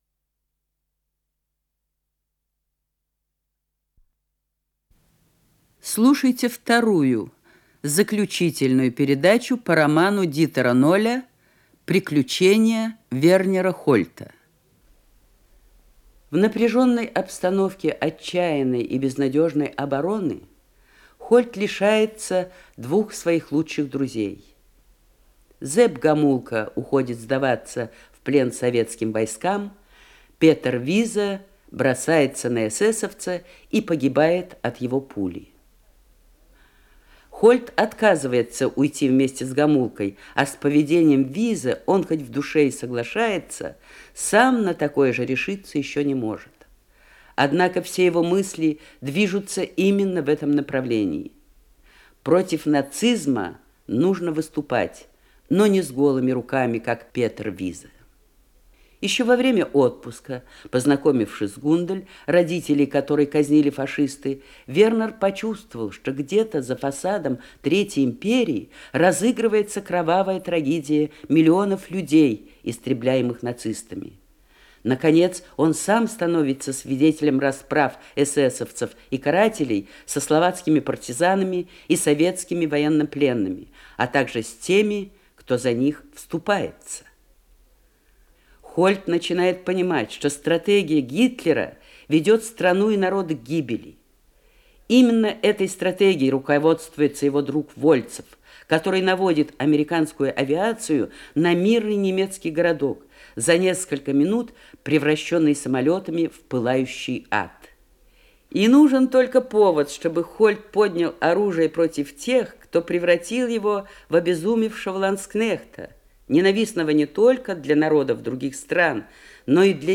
Композиция, страницы романа, передача 2-я